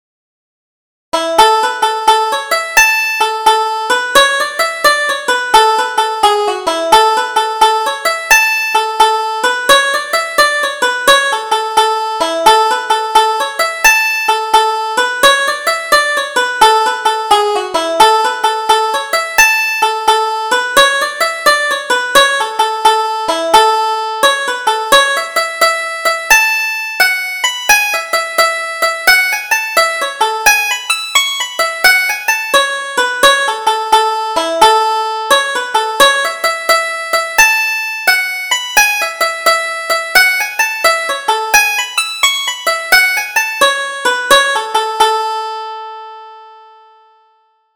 Double Jig: Jack of All Trades